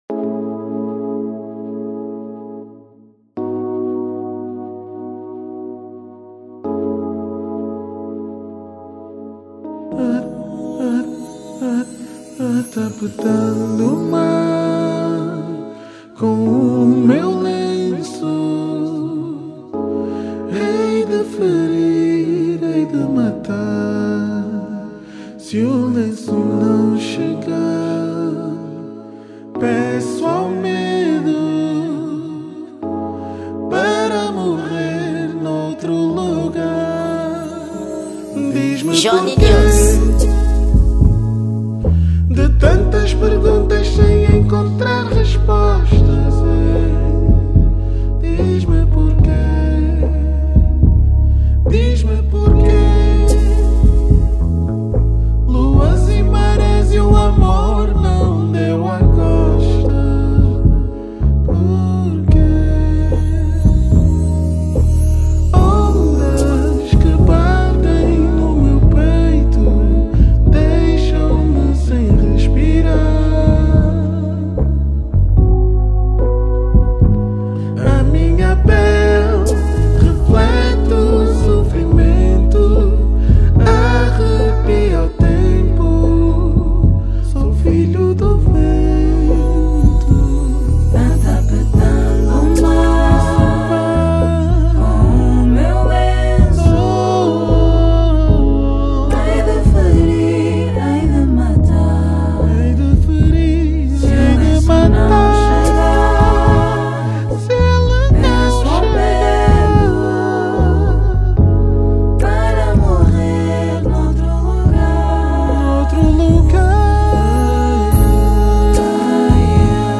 Gênero: Soul